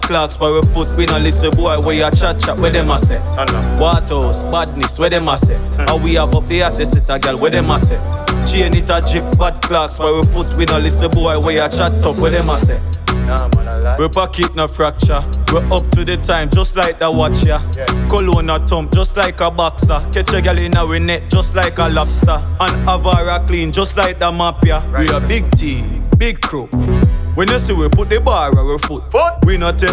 Жанр: Реггетон
# Reggae